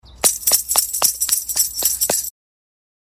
ﾊﾟﾁﾊﾟﾁﾊﾟﾁﾊﾟﾁﾊﾟﾁﾊﾟﾁﾊﾟﾁﾊﾟﾁ
ｼｬﾝｼｬﾝｼｬﾝｼｬﾝｼｬﾝｼｬﾝｼｬﾝｼｬﾝ